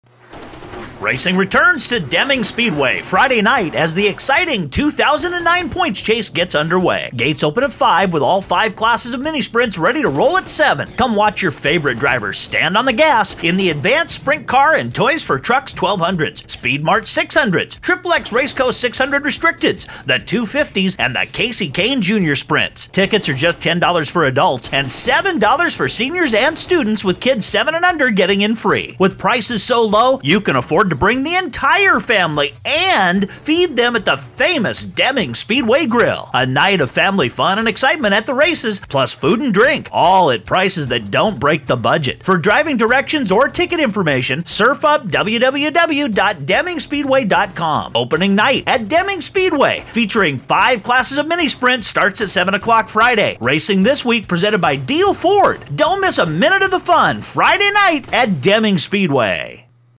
good radio ad.